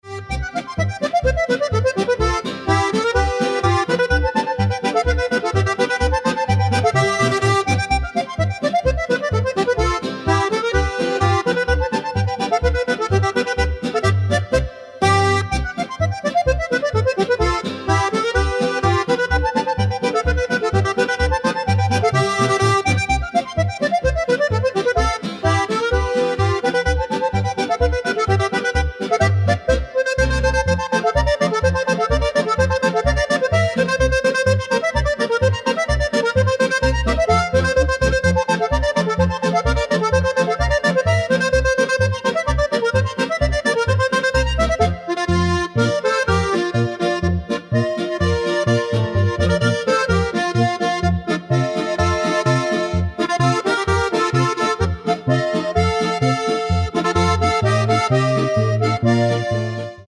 Schuhplattler und Dirndl